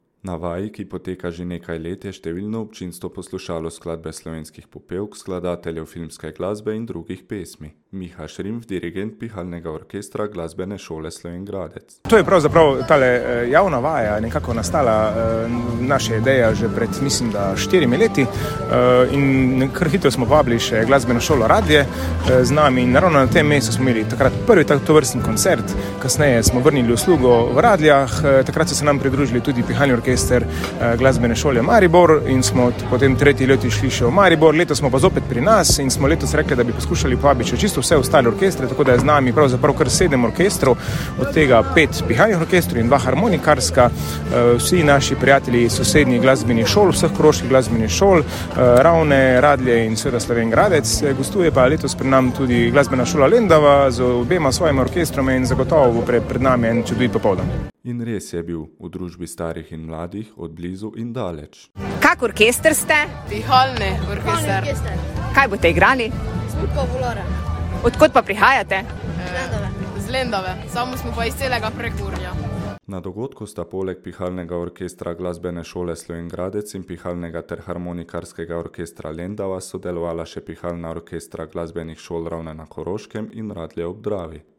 Slovenske popevke in filmska glasba v izvedbi pihalnih ter harmonikarskih orkestrov
Javna vaja pihalnega orkestra Glasbene šole Slovenj Gradec z gosti
Včerajšnja javna vaja pihalnega orkestra Glasbene šole Slovenj Gradec z gosti od blizu in daleč je navdušila nemalo številne obiskovalce.
PIHALNI ORKESTER.mp3